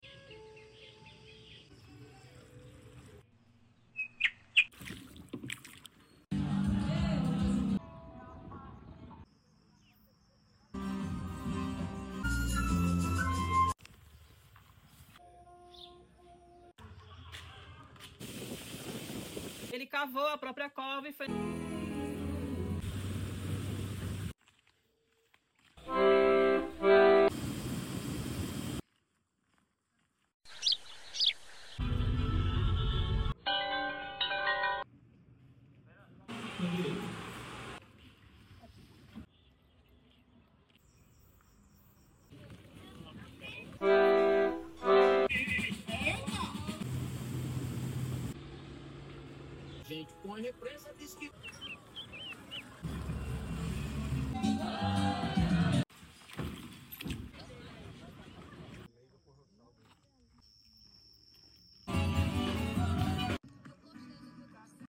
📍Sons do Sertão 🇧🇷 Sem música, apenas os sons reais de Piranhas – o rio correndo, pássaros cantando, risos nas ruas e o dia a dia dessa cidade encantadora.